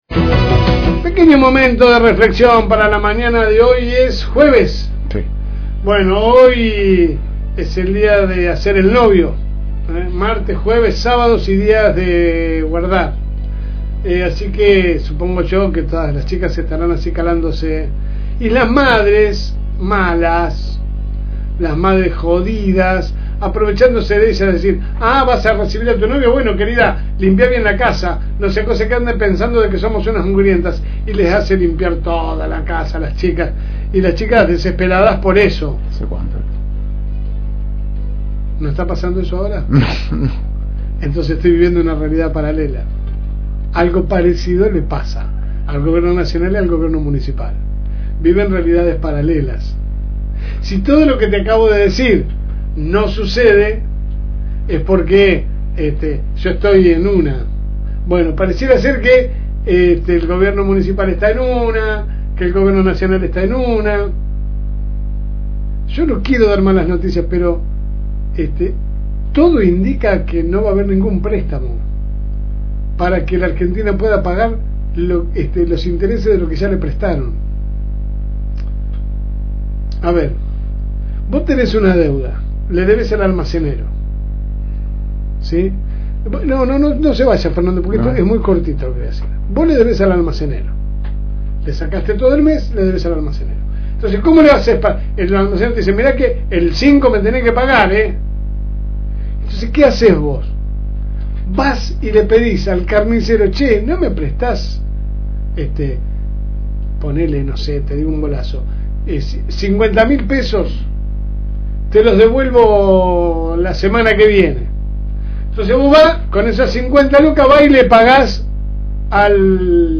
Que lo podes escuchar de lunes a viernes de 10 a 12 HS por el aire de la FM 102.9
La editorial en cuestión a continuación